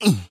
snd_oof3.ogg